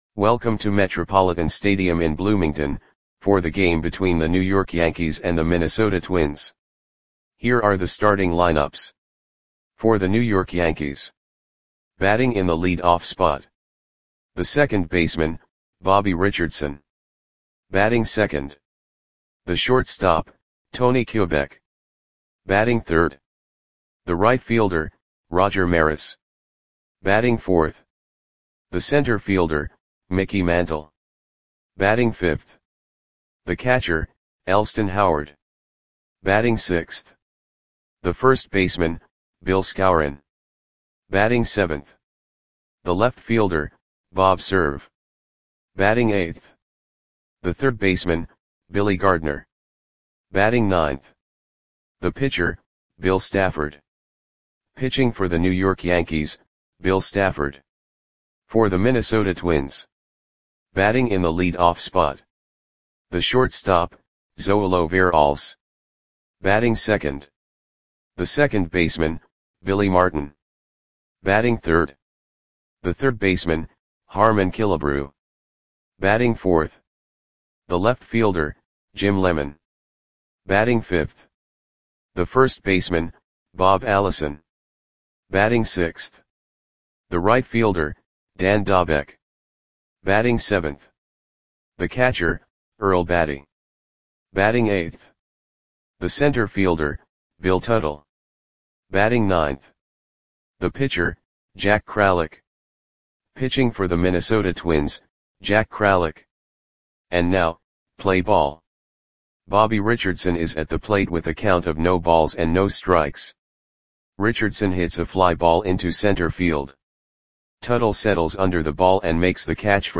Audio Play-by-Play for Minnesota Twins on June 25, 1961
Click the button below to listen to the audio play-by-play.